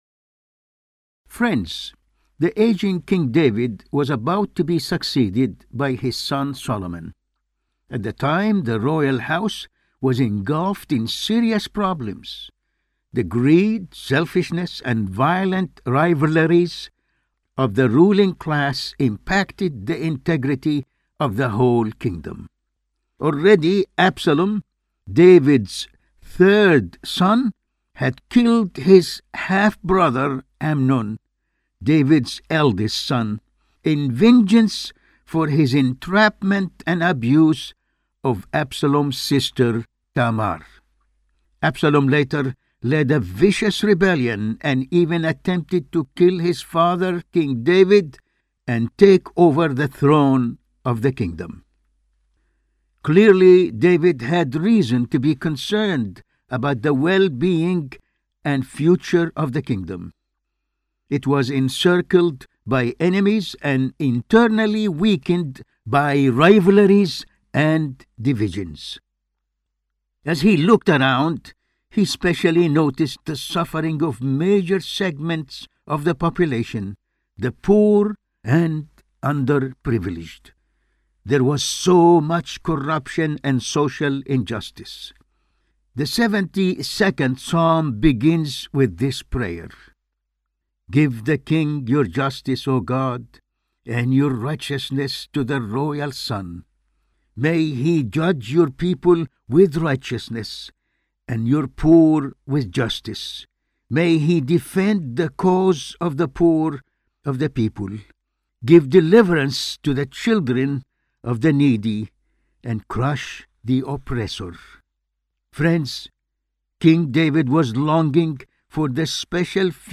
Latest Sermon: